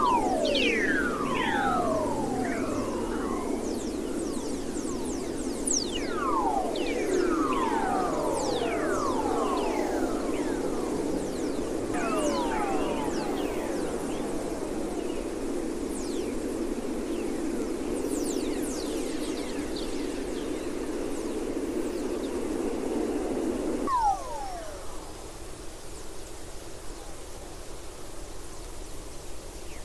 This is the from Van Allen Probes B Waves Bu Bv and Bw magnet antennas from this hour 2013-04-16 01:00 UTC.